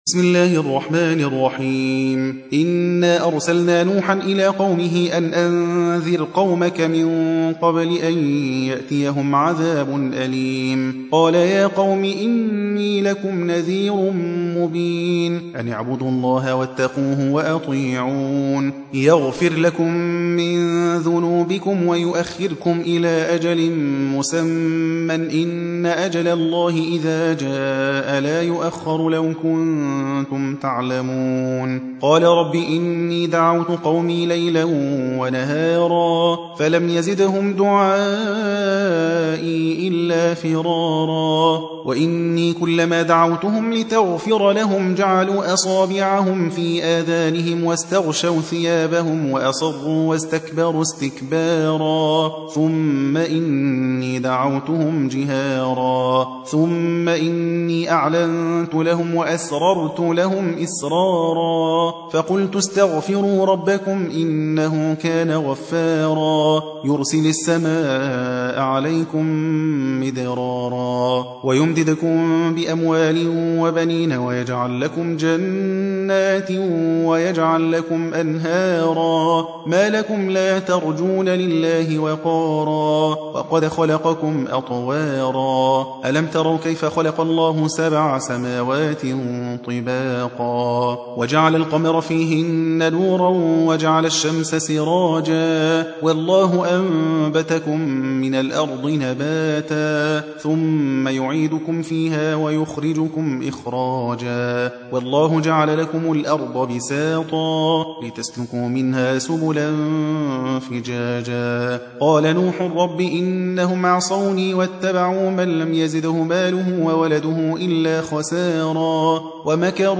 71. سورة نوح / القارئ